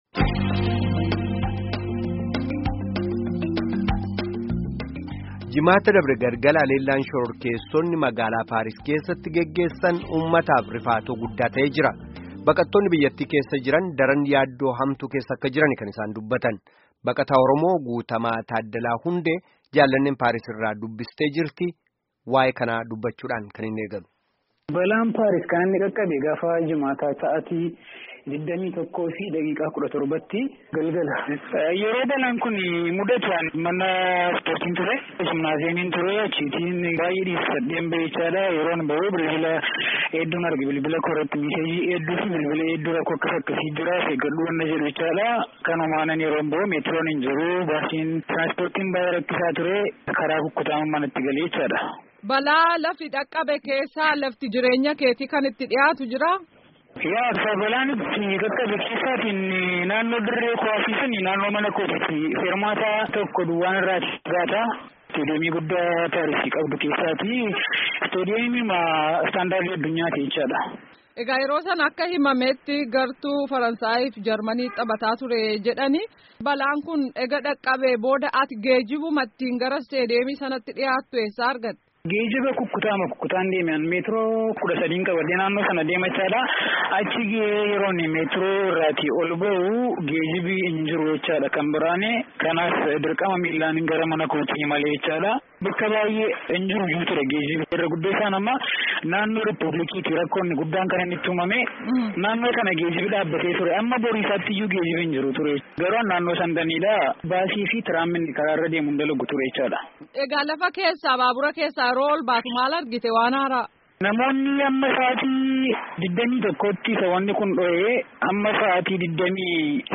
Gaaffii fi deebii koolu galaa Paaris keessaa waliin geggeessame dhaga'aa